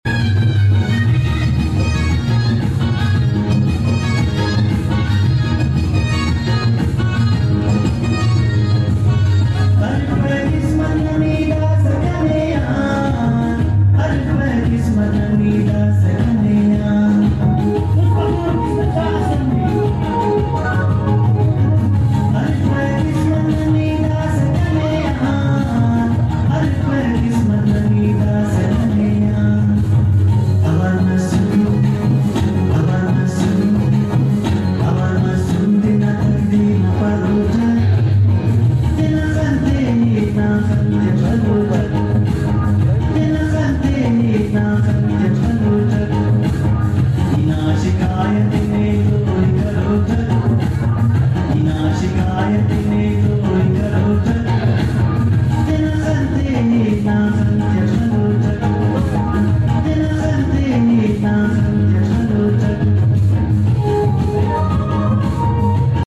brahvi song